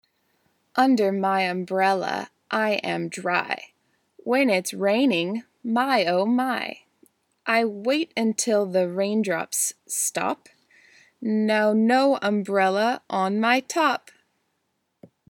Comptine 4 Under my umbrella